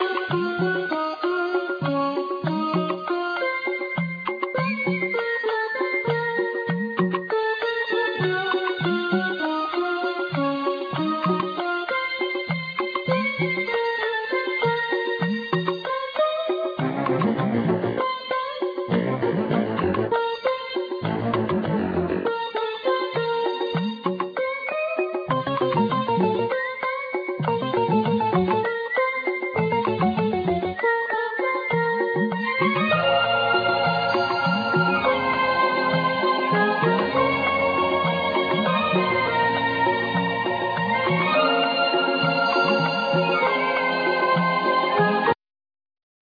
Keyboards
Drums,Percussion
Guitar,sitar
Bass,Backing Vocal
Trumpet
Tenor saxophne,Flute
Violin
Lead vocal
Duduk